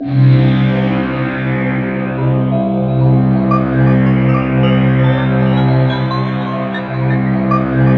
SYN JD80005R.wav